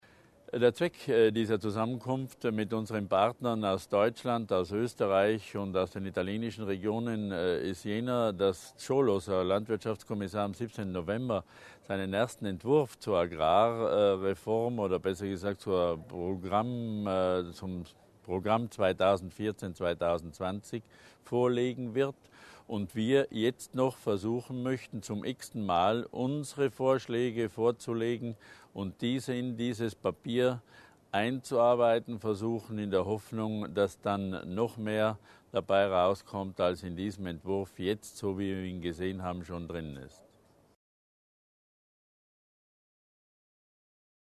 Landesrat Berger über die Wichtigkeit des Treffens